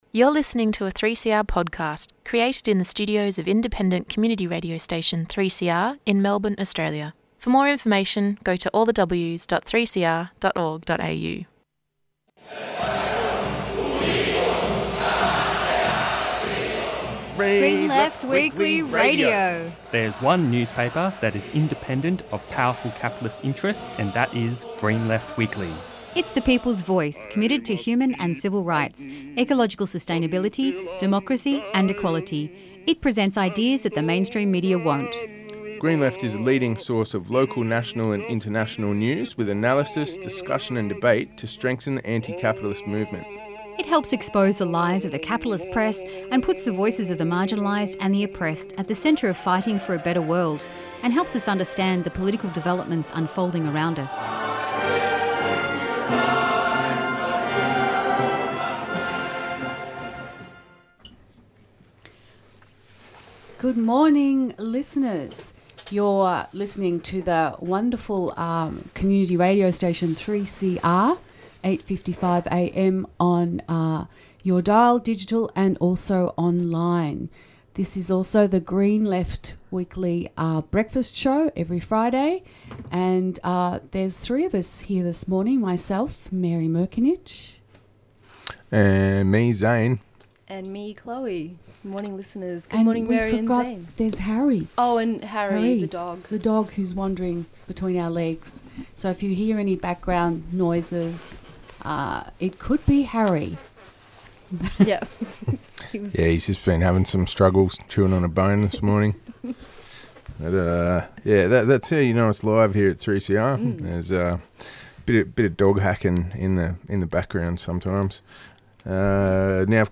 News articles, dicussion and analysis, upcoming events and interviews.